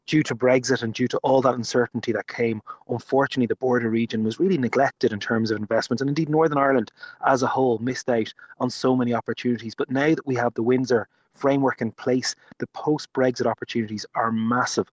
Speaking at the Cross-Border Trade and Economic Conference yesterday, Neale Richmond urged politicians to focus on rebuilding an all-island economy.